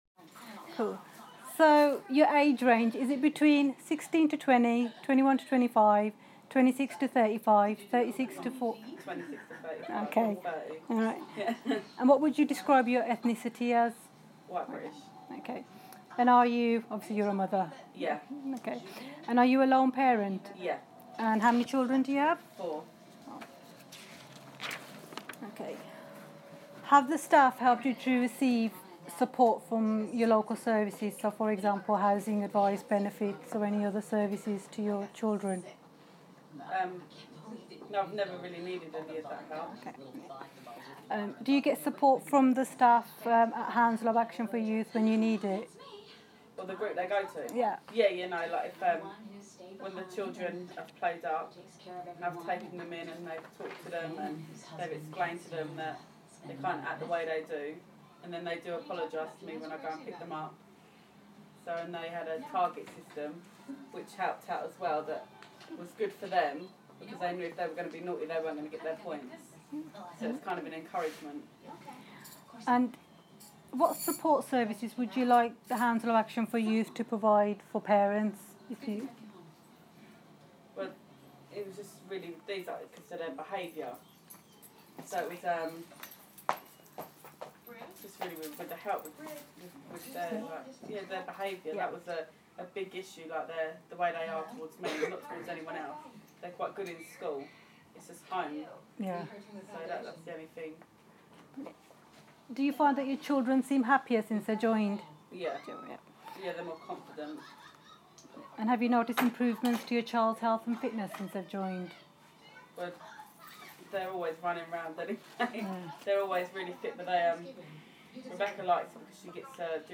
jyip parent interview